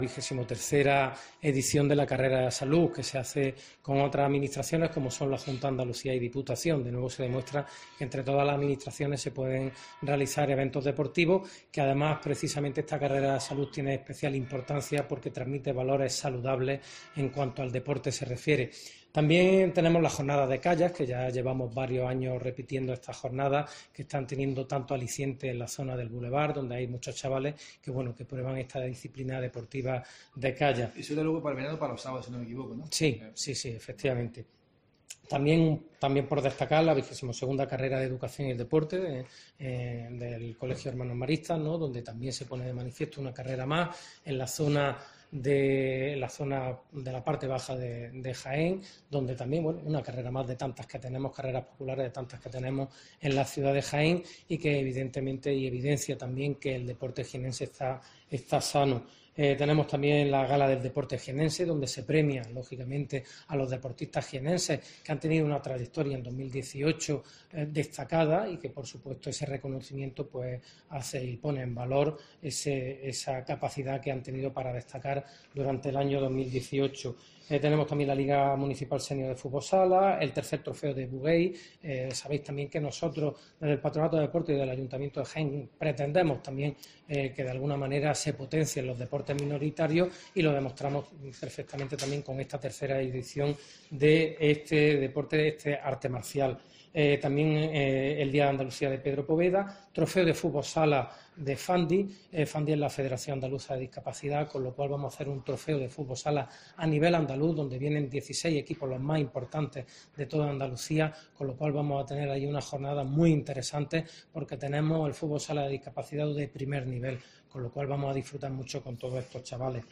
Chema Alvarez, concejal de deportes del ayto de Jaén, nos habla sobre el Consejo Deportes